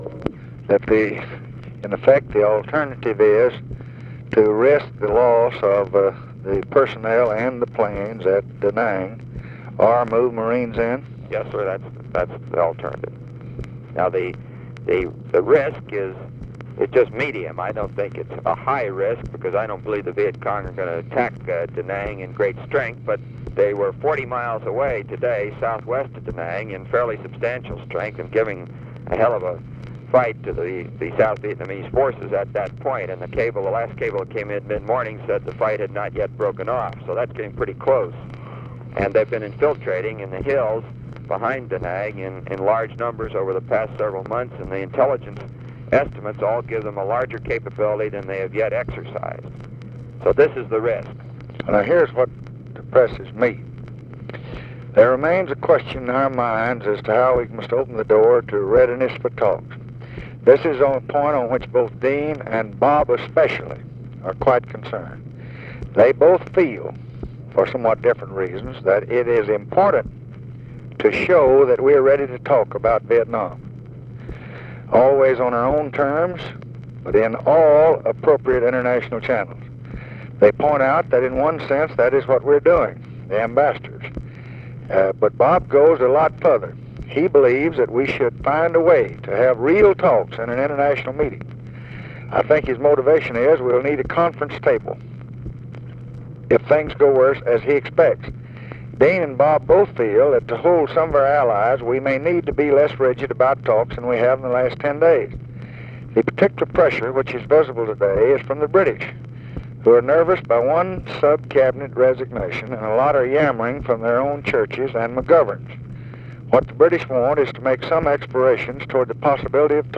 Conversation with ROBERT MCNAMARA, March 6, 1965
Secret White House Tapes